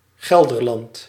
[ˈɣɛldərˌlɑnt] (?·i) en neerlandés actual: Gelderland, d'antiguo Gelre) ye una de les dolce provincies que conformen el Reinu de los Países Baxos.